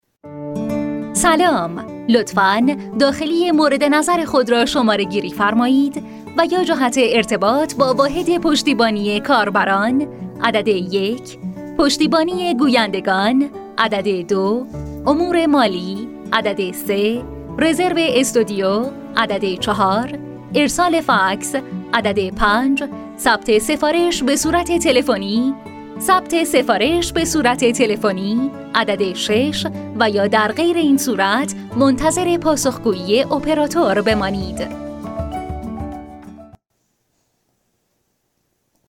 Female
Young
Adult
IVR